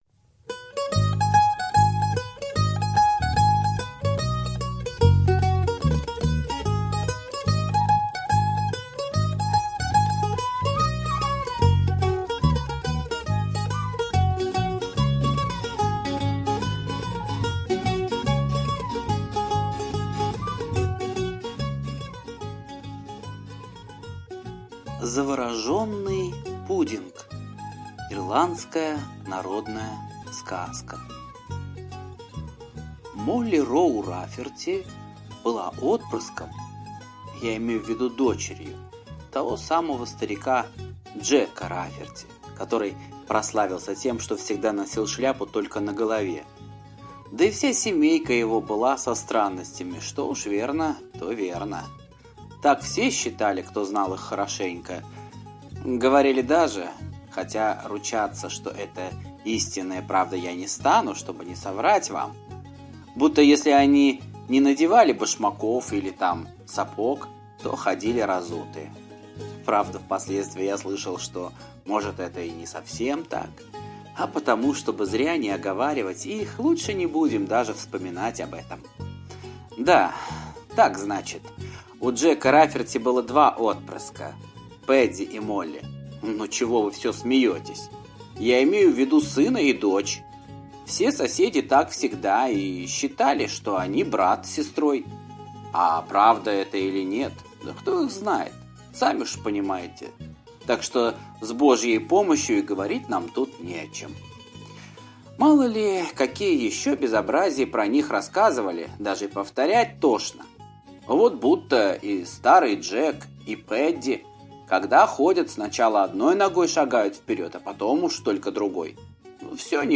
Завороженный пудинг - ирландская аудиосказка - слушать онлайн